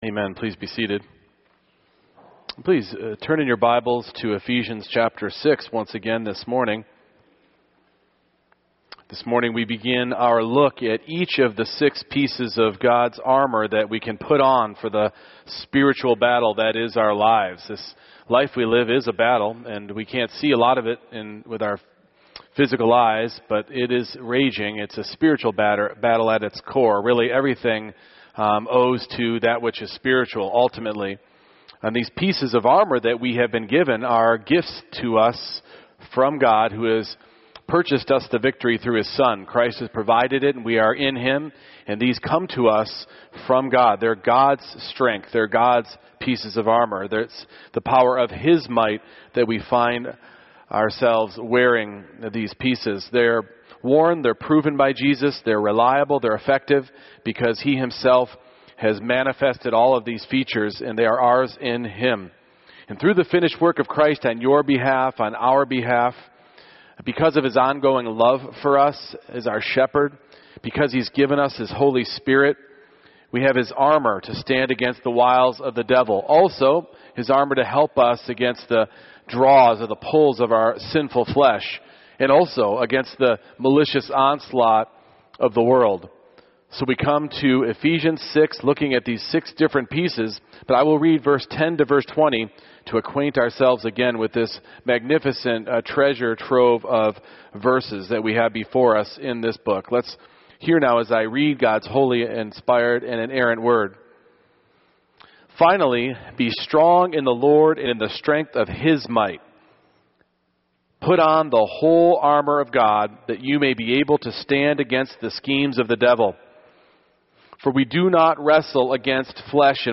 Ephesians 6:14 Service Type: Morning Worship Truth is essential to the Christian life and our stand against evil.